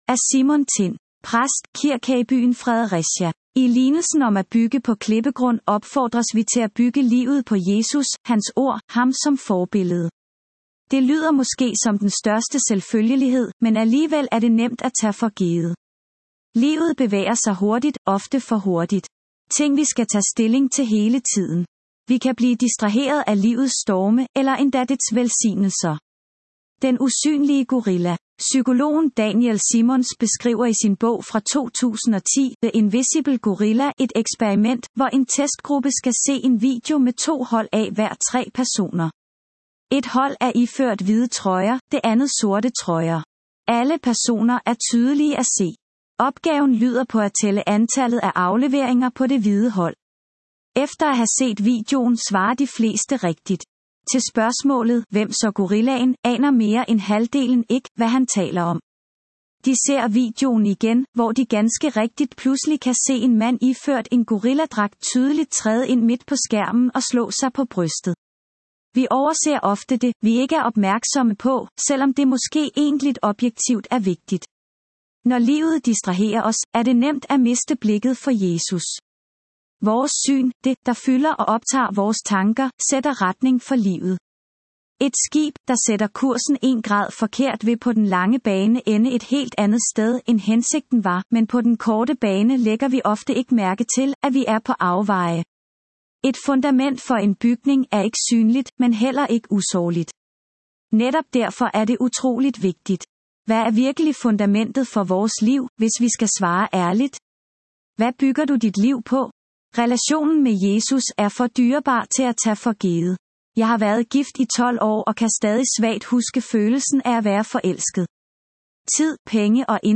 Ugens Prædiken